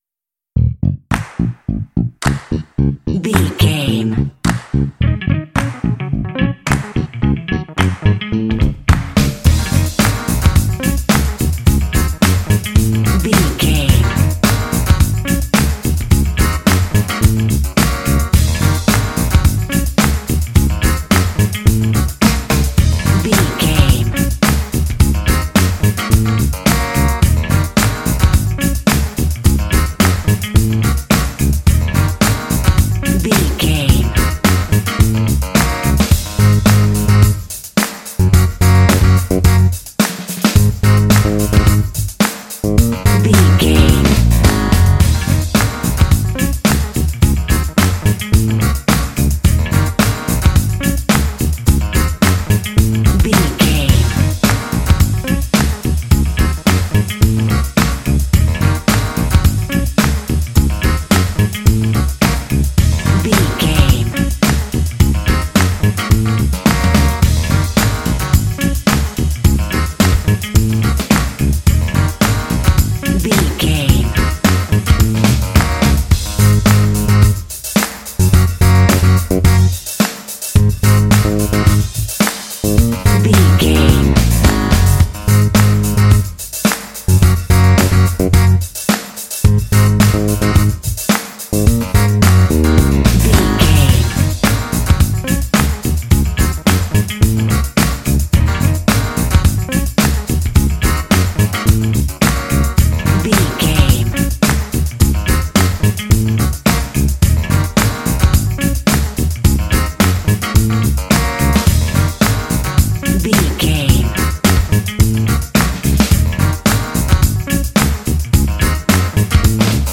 Uplifting
Dorian
E♭
groovy
smooth
bass guitar
electric guitar
drums
synthesiser
percussion